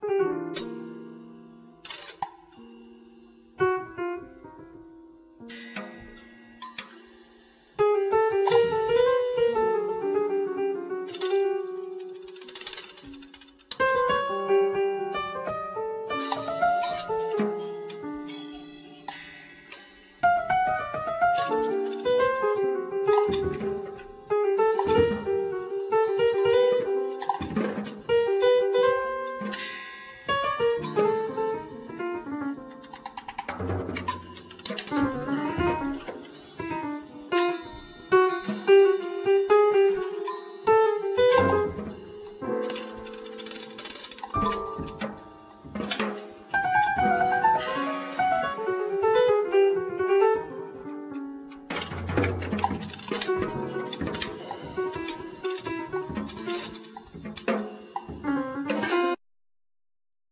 Piano, Percussion, Prepared piano
Drums, Gong